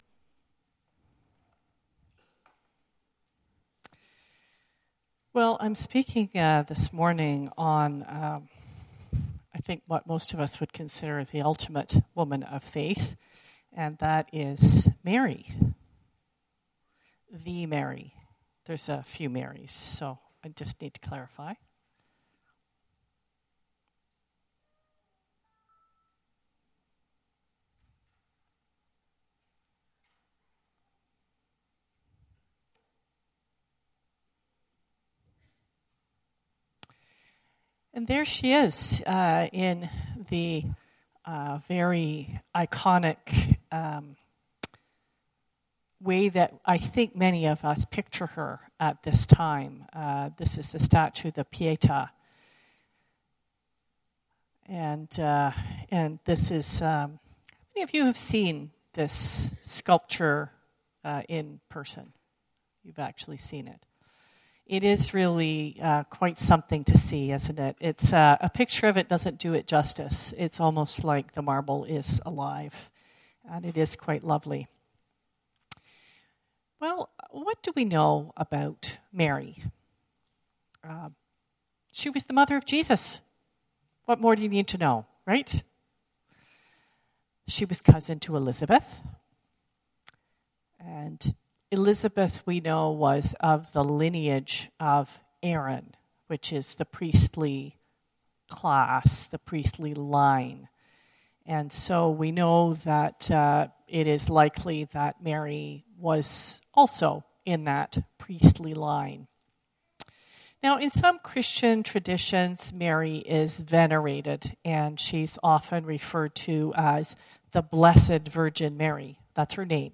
This sermon is based on the story of Mary.